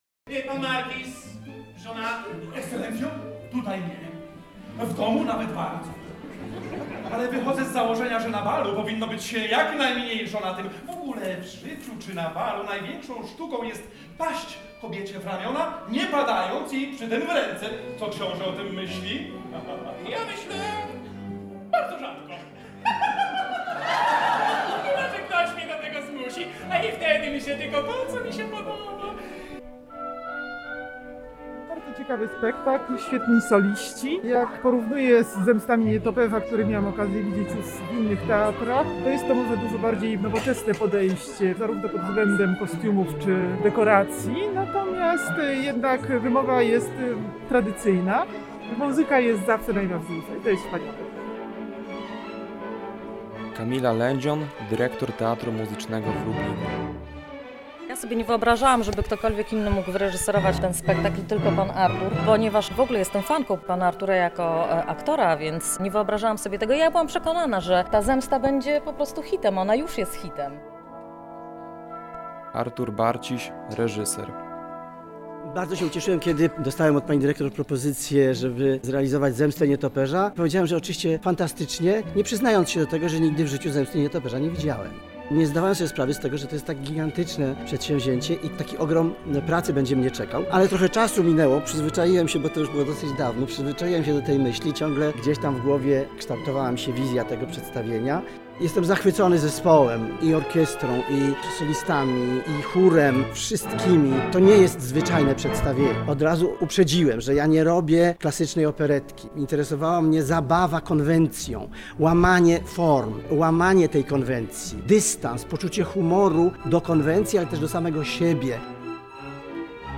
O szczegółach dotyczących spektaklu opowiedział między innymi reżyser – Artur Barciś.
zemsta-nietoperza-relacja.mp3